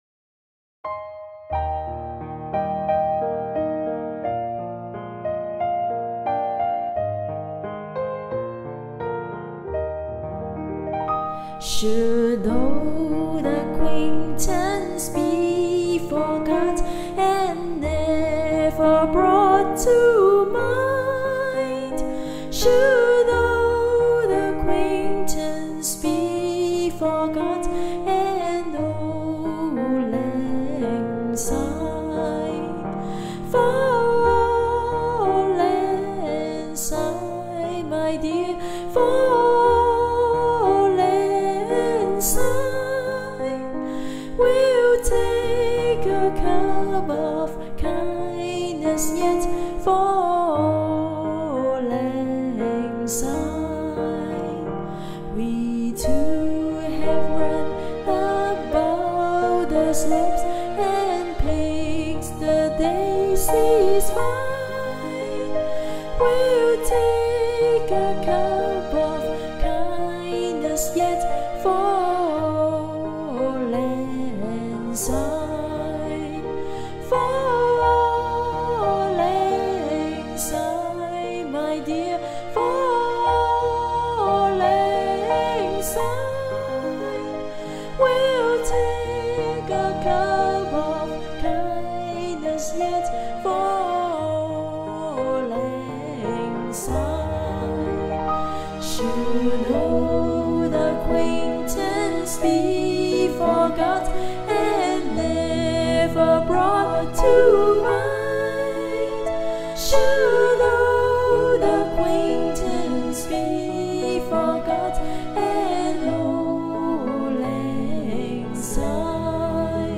Rearranged in 2 parts harmony by
farewell song